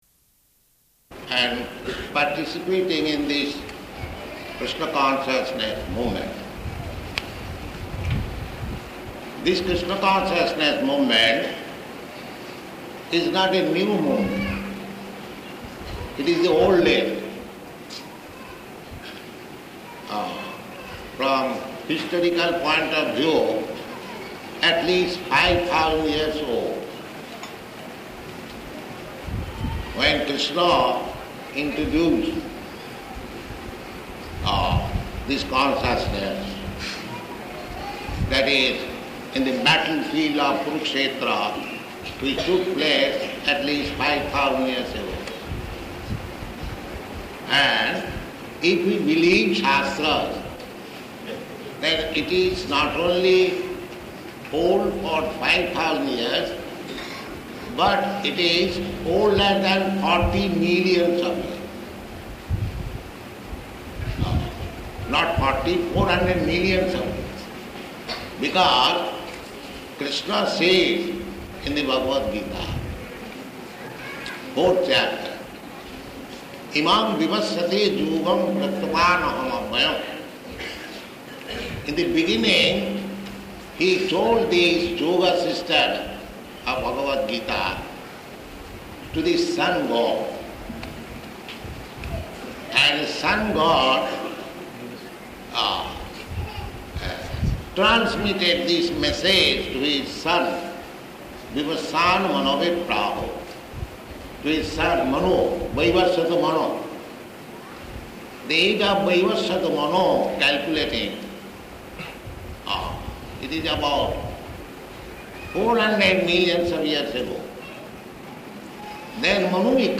Lecture
Lecture --:-- --:-- Type: Lectures and Addresses Dated: February 18th 1972 Location: Visakhapatnam Audio file: 720218LE.VIS.mp3 Prabhupāda: ...and participating in this Kṛṣṇa consciousness movement.